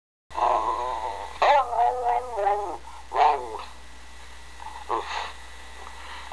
His Growl
There is a pause and then you hear "umph!"
It's a scream.
Growl.wav